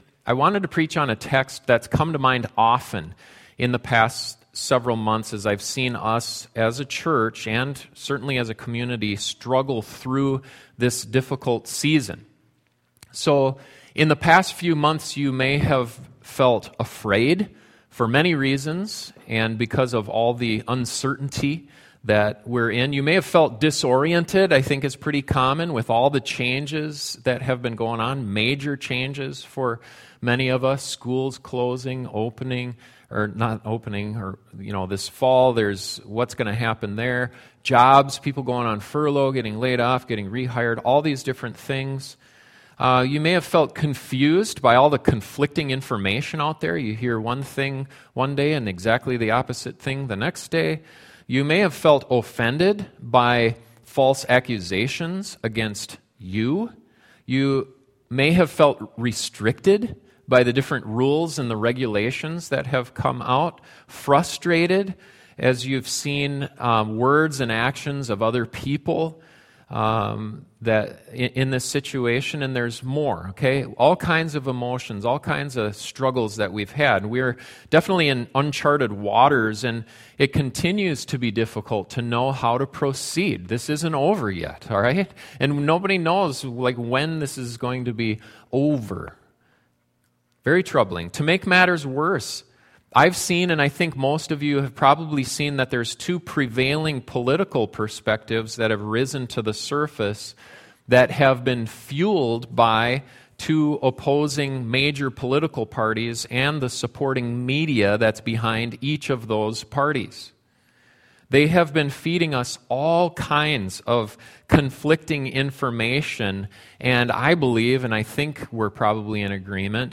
Pathway to Perfect Harmony Colossians 3:11-17 This is going to be a stand-alone sermon today because we’ve got a couple weeks before I start the next series.